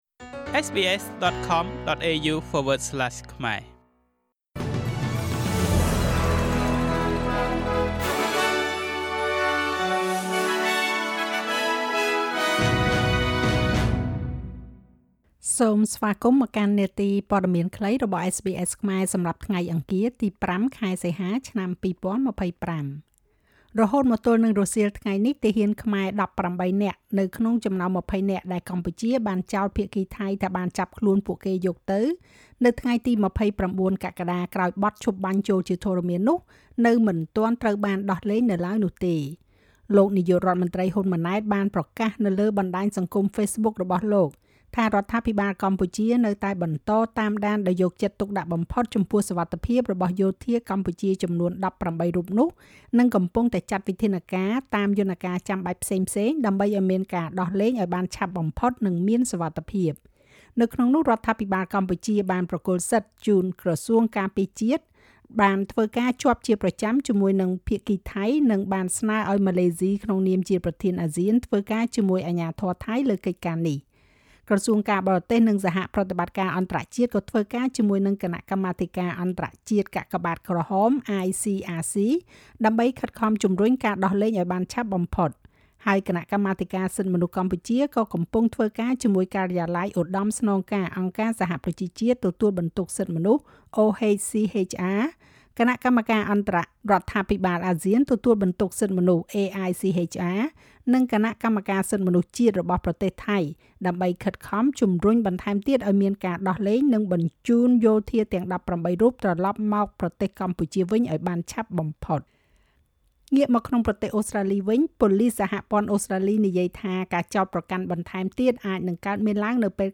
នាទីព័ត៌មានខ្លីរបស់SBSខ្មែរ សម្រាប់ថ្ងៃអង្គារ ទី៥ ខែសីហា ឆ្នាំ២០២៥